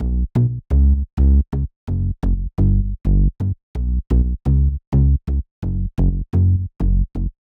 VDE1 128BPM Full Effect Bass Root B SC.wav